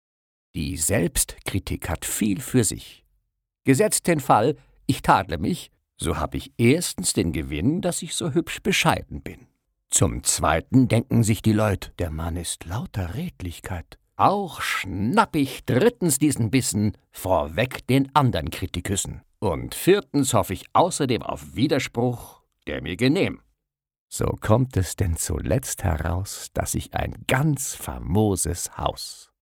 Lyrik
Die Selbstkritik… – Gedicht von Wilhelm Busch (1832-1908)